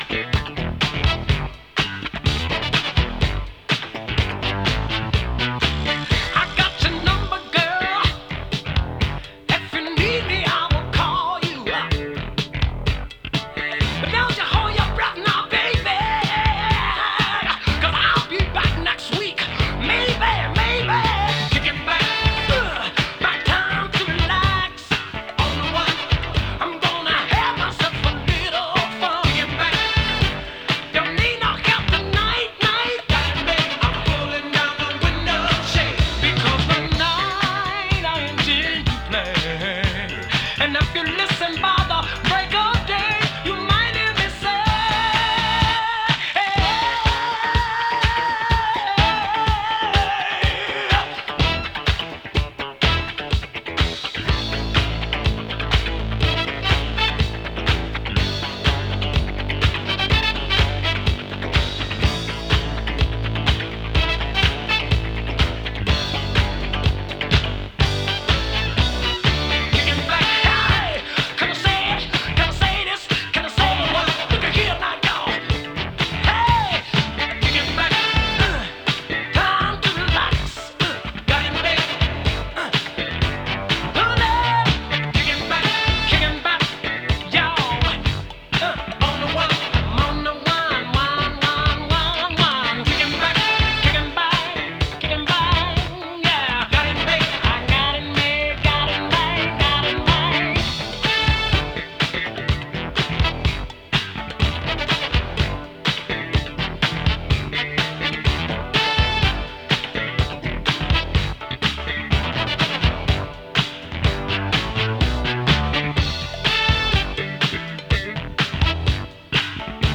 パワフルに前進していくようなディスコ・ファンク！
強靭ファンク・サウンドを下敷きにしたグルーヴと迫力満点のヴォーカルがマッチしたディスコ・クラシック。
♪Special Extended Version (7.58)♪